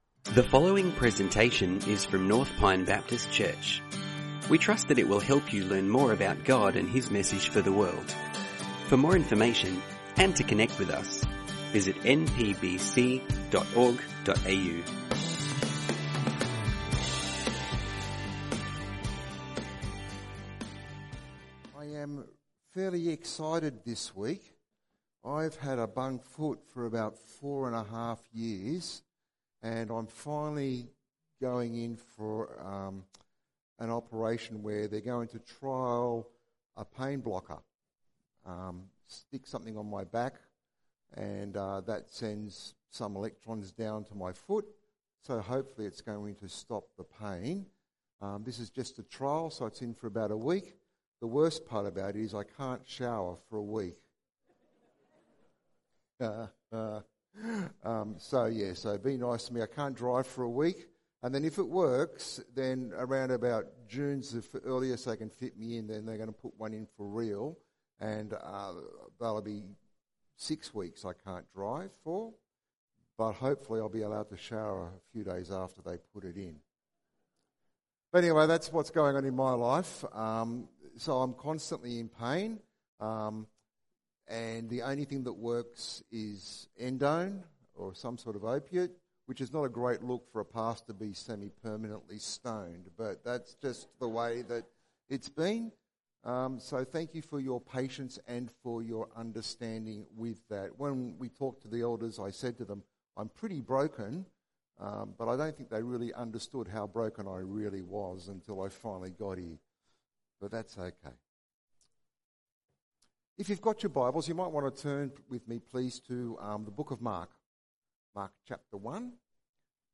Sermons | North Pine Baptist Church